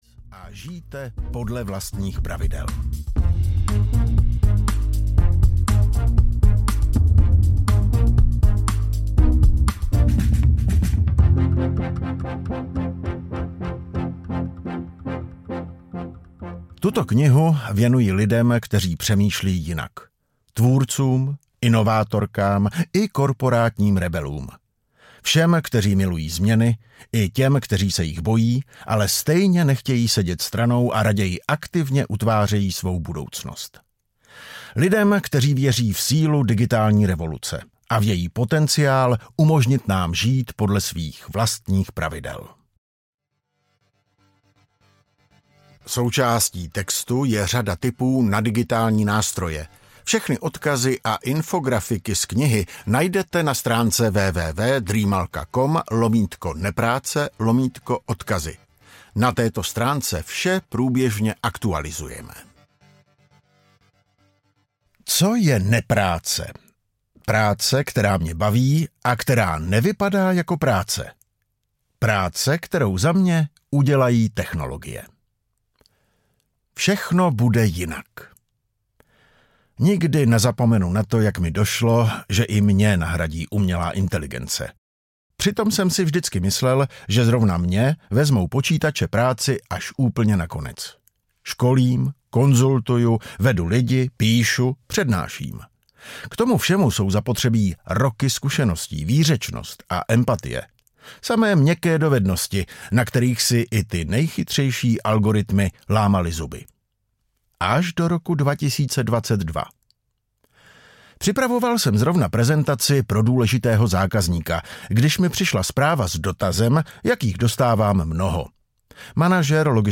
Budoucnost nepráce audiokniha
Ukázka z knihy